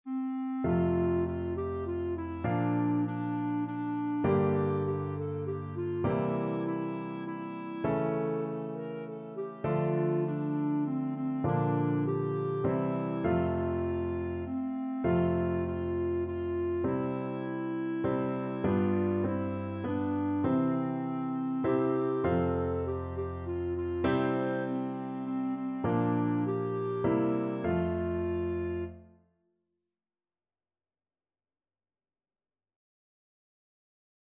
Free Sheet music for Clarinet
F major (Sounding Pitch) G major (Clarinet in Bb) (View more F major Music for Clarinet )
Moderato
3/4 (View more 3/4 Music)
Clarinet  (View more Beginners Clarinet Music)
Traditional (View more Traditional Clarinet Music)